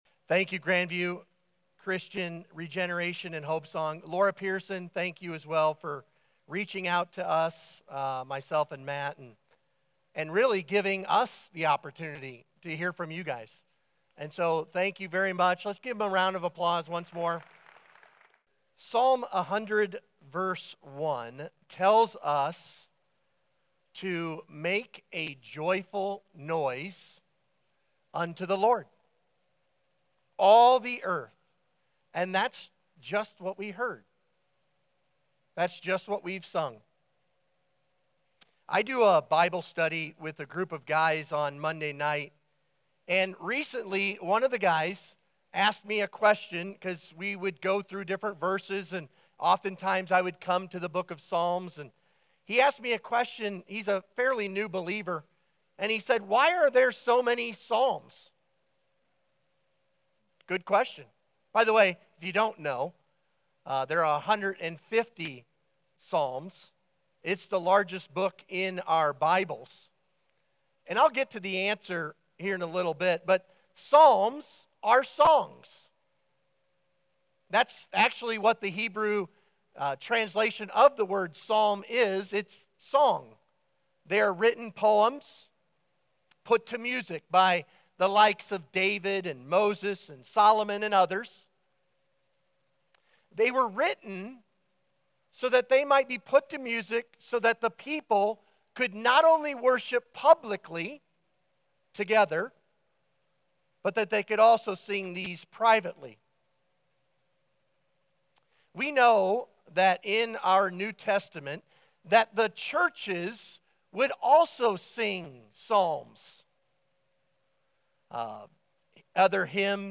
Sermon Discussion Read Psalm 100 together.